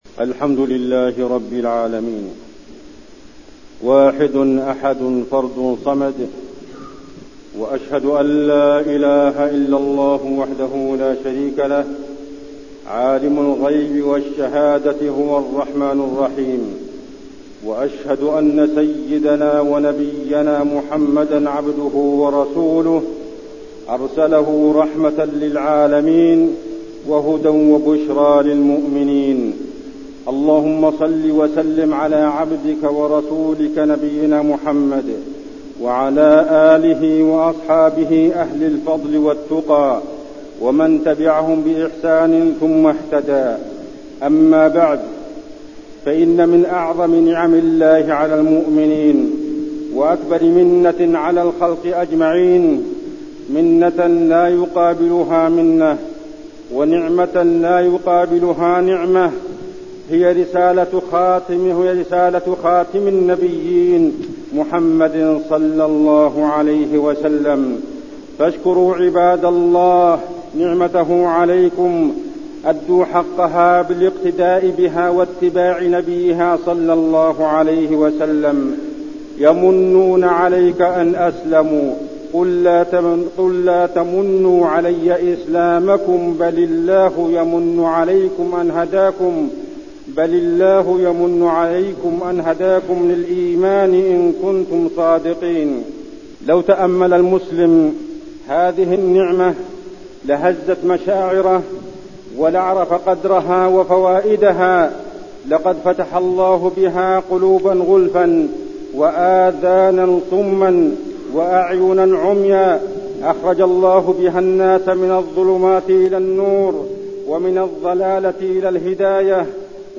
تاريخ النشر ٢٧ رجب ١٤٠٧ هـ المكان: المسجد النبوي الشيخ: عبدالله بن محمد الزاحم عبدالله بن محمد الزاحم نعمة الإسلام ومحاربة الفتن